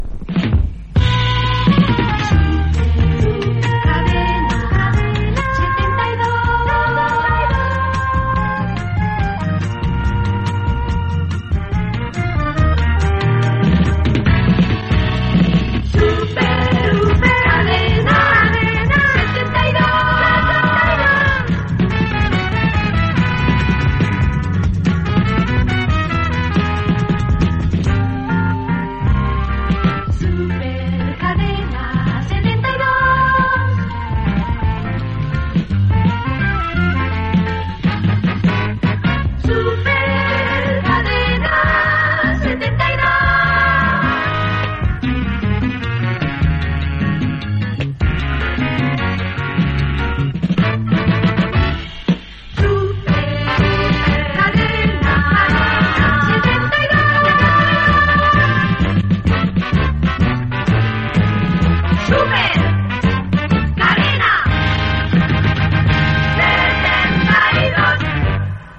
Indicatiu cantat de la cadena